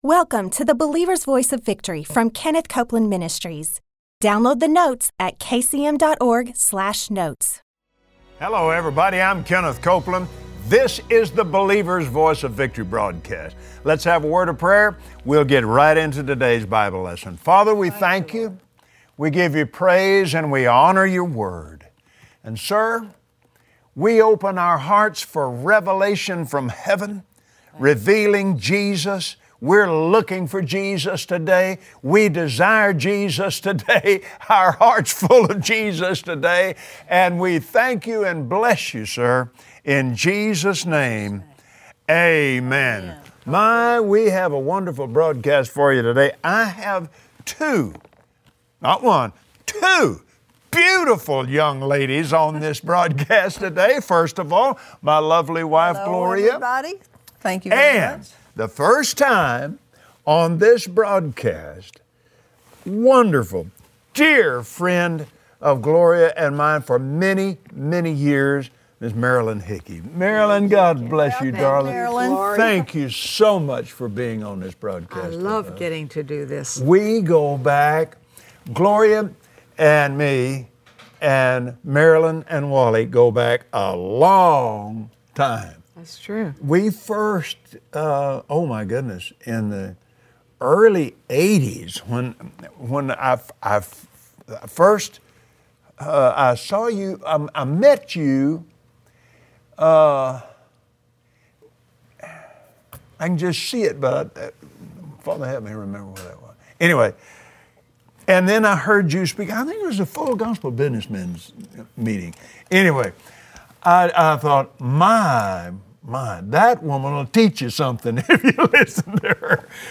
Today Kenneth and Gloria Copeland welcome special guest, Marilyn Hickey. Join them as they take a look at the Old Testament and reveal Jesus in every book of the Bible.